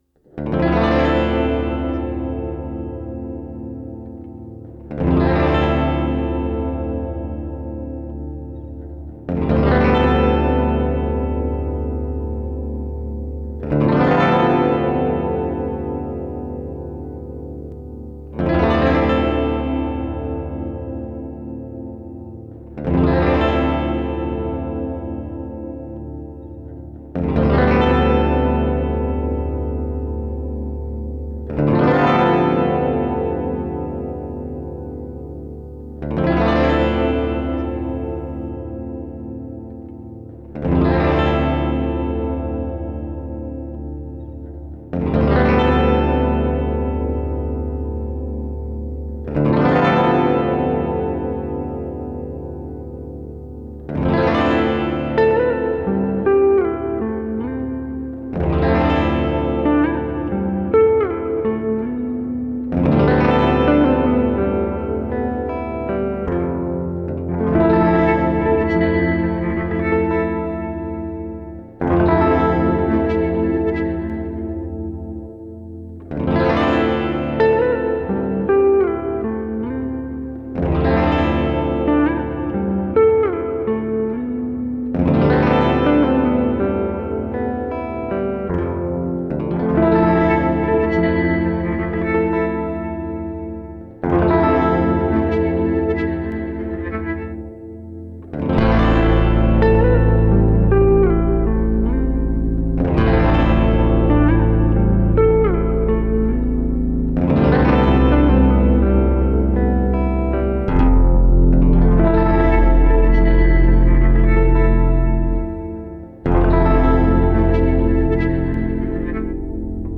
4/4, noire = 50, (drop Ré).
Les croches (du thème) sont ternaires.
Les enregistrements sont joués avec un tempo noire = 54.
Le morceau est joué avec en drop Ré, le thème peut être joué en drop Fa (si le drop n'est pas possible, ne pas jouer la corde grave).
Ensuite, vient toute une ambiance aquatique., avec des baleines, des bloop, etc.